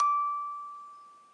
八音盒单音 " 00 d5
Tag: 音高D5 单音符 音乐盒